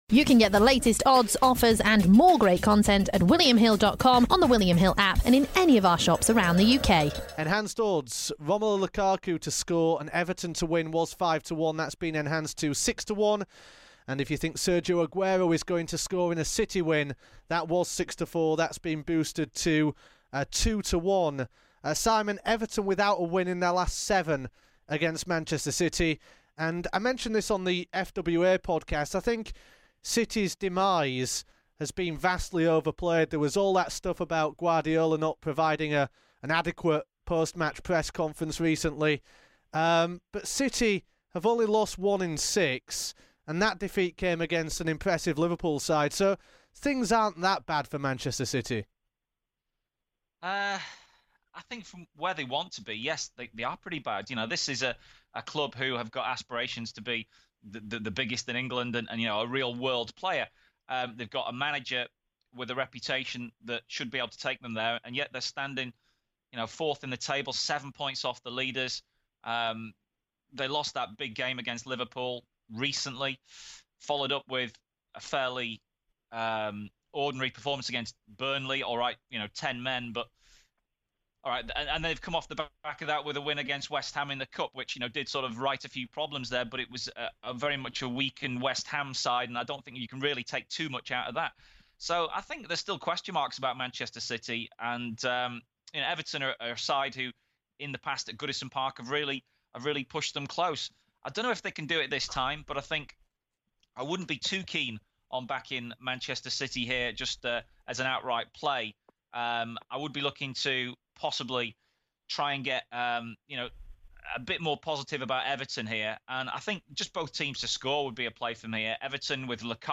LISTEN: Premier League Match Preview.